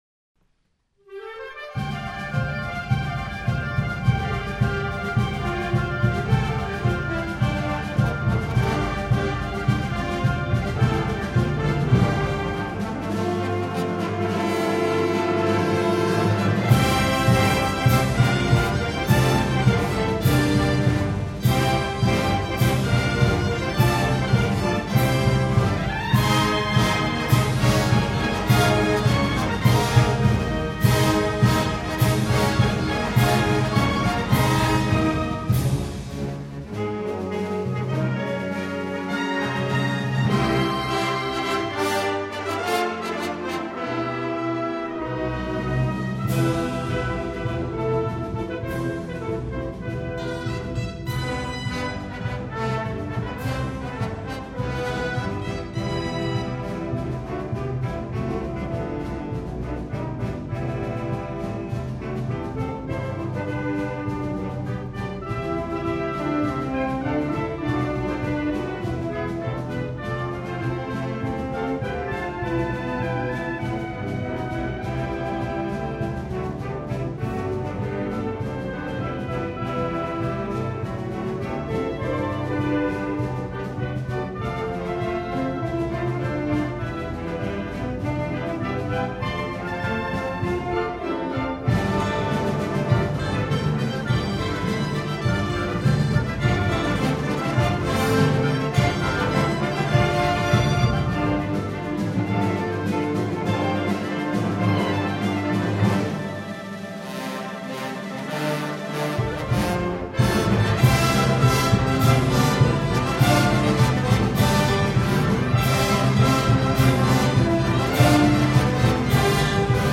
Gender: Christian marches